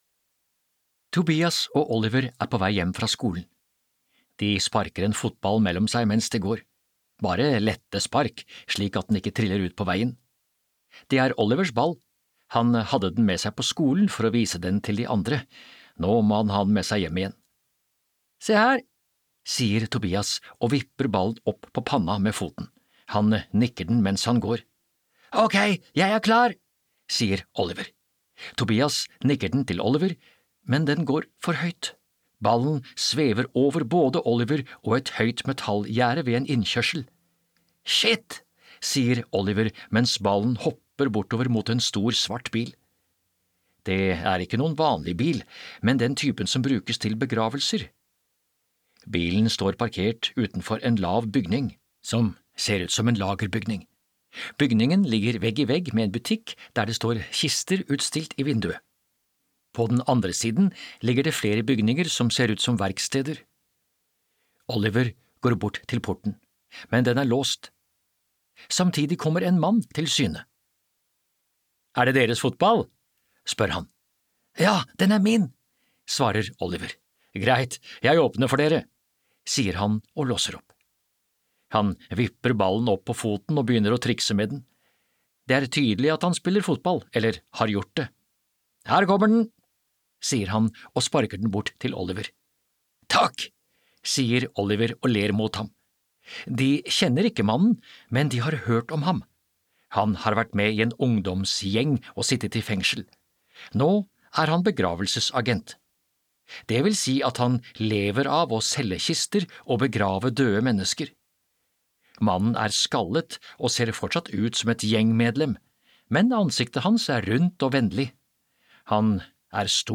Nedlastbar lydbok